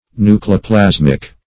Search Result for " nucleoplasmic" : The Collaborative International Dictionary of English v.0.48: Nucleoplasmic \Nu`cle*o*plas"mic\, a. (Biol.)